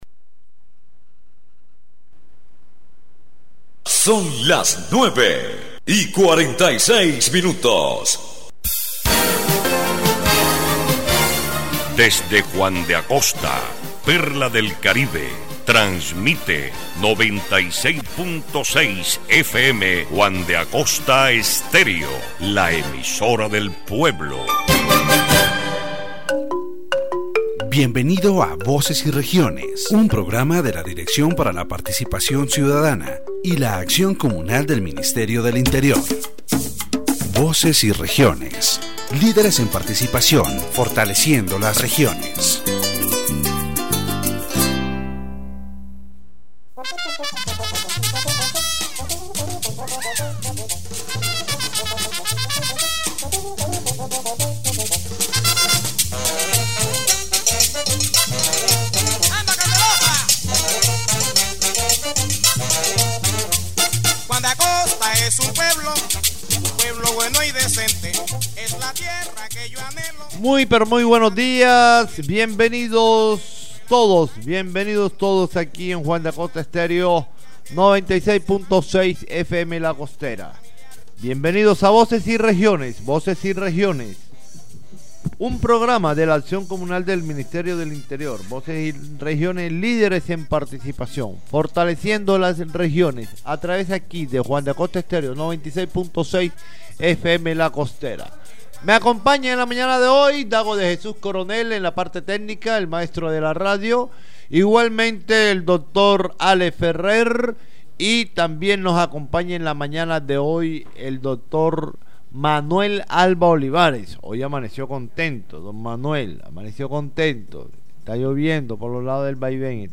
The radio program "Voces y Regiones", broadcasted on Juan de Acosta Exterio 96.6 FM, focuses on the importance of citizen participation in elections in Colombia. The panelists discuss the key role of voters in electoral processes, emphasizing the need to ensure transparency and citizen oversight.